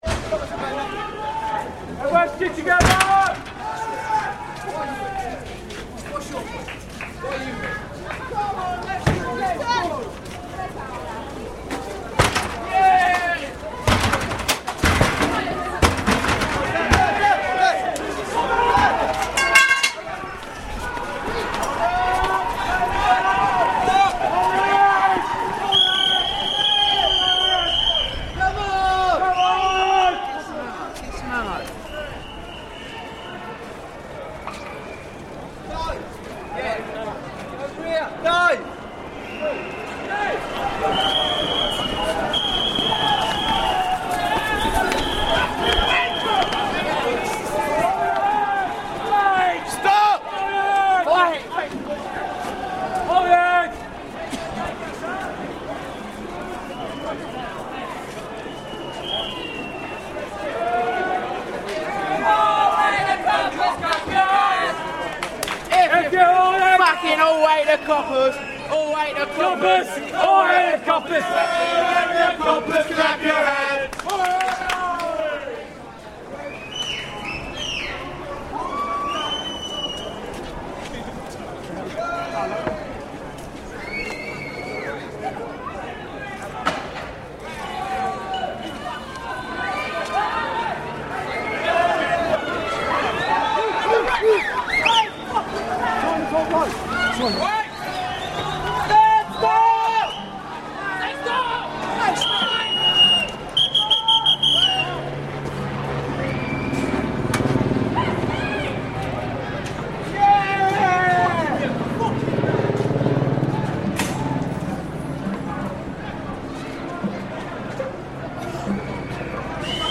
The breakdown of law and order: May Day 2001, Tottenham Court Road in central London. Watched by police helicopters circling above, but with few police in the street, anti-capitalist rioters are roaming around almost freely, smashing the windows of banks and shops while cheering each other on, occassionally running from a handful of police officers."